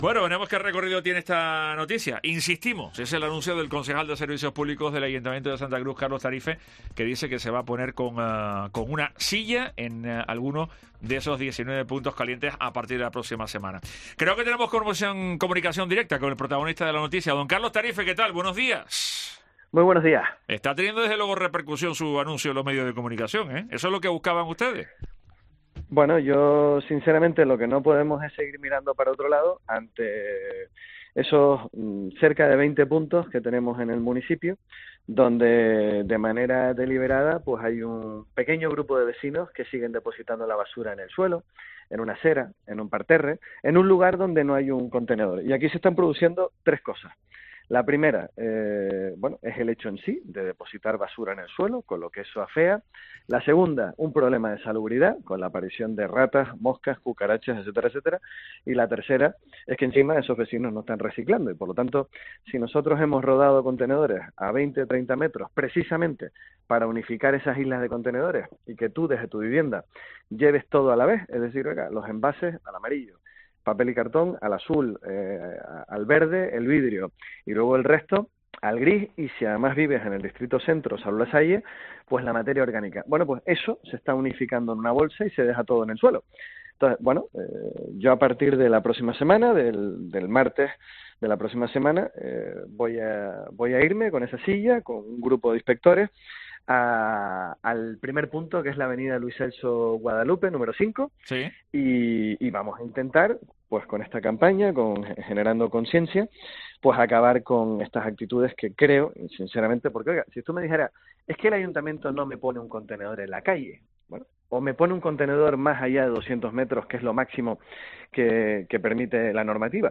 Lo ha contado el propio concejal este miércoles en los micrófonos de Herrera en COPE Tenerife.